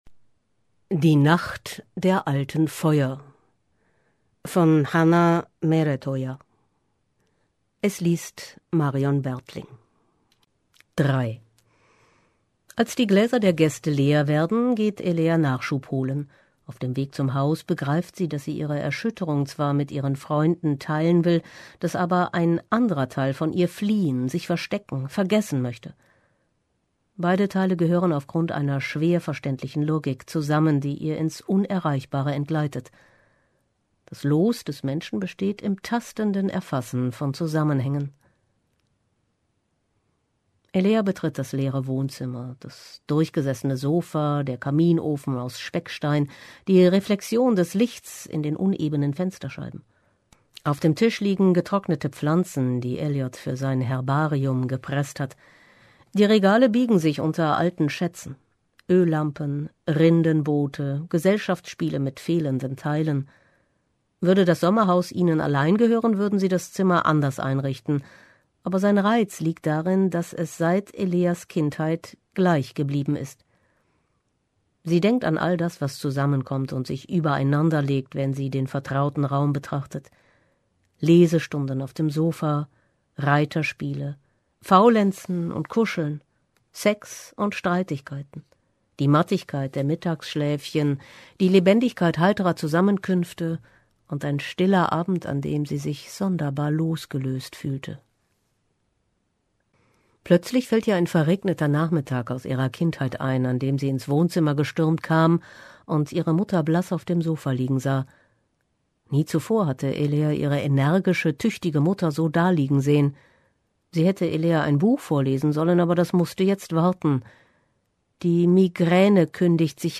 Diesen eindringlichen Roman liest für Sie